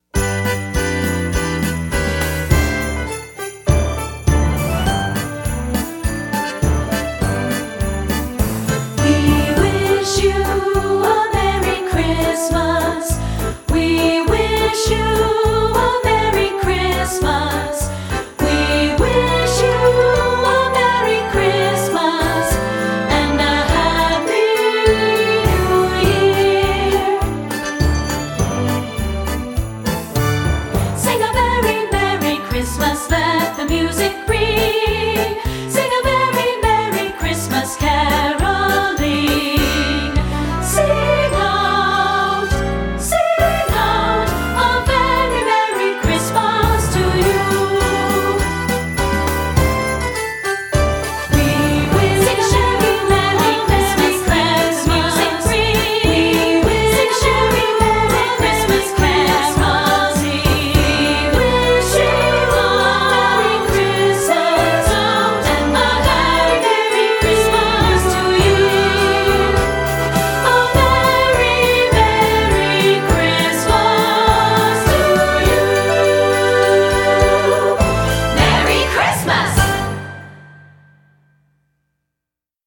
Instrumentation: choir (2-Part)
secular choral